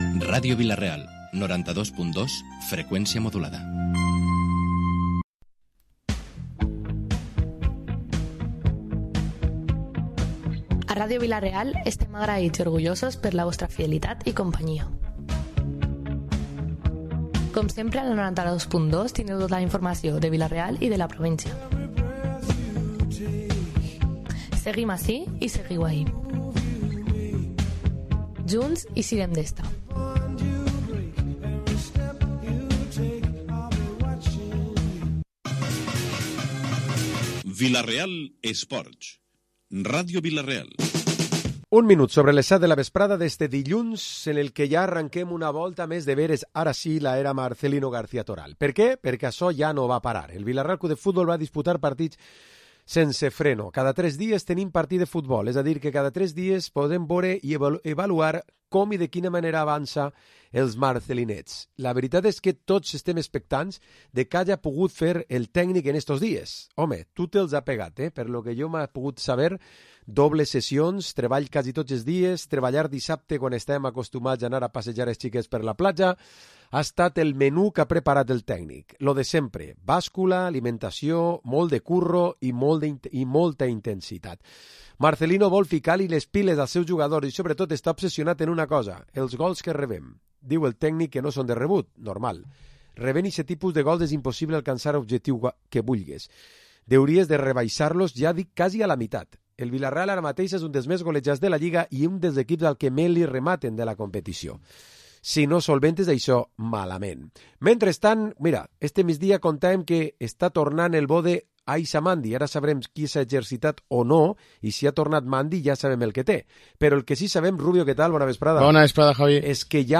Programa esports tertúlia dilluns 20 de Novembre